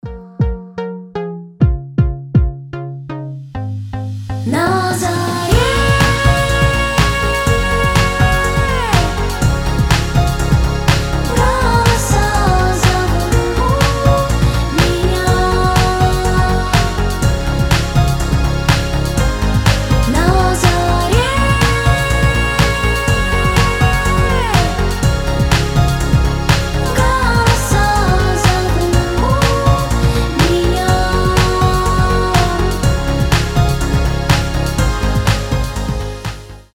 • Качество: 320, Stereo
мелодичные
Electronic
Cover
красивый женский голос
indie pop